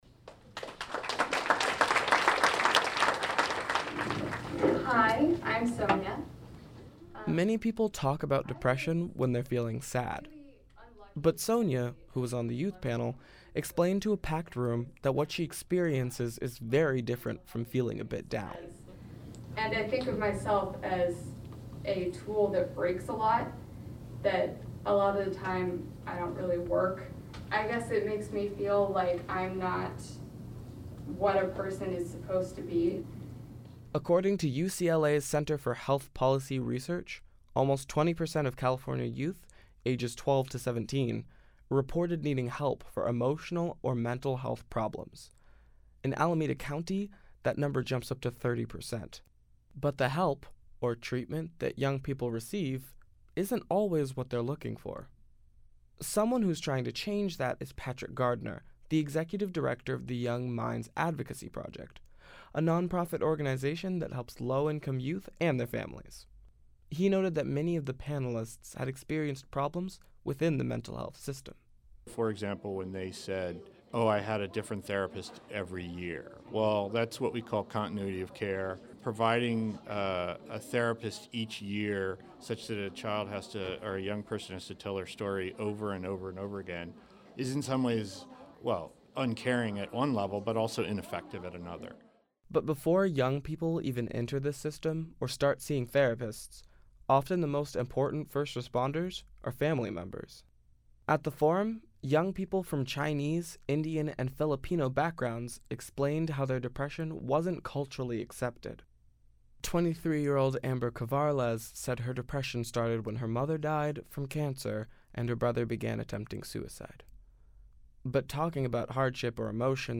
At the forum, young people from Chinese, Indian and Filipino backgrounds explained how their depression wasn’t culturally accepted.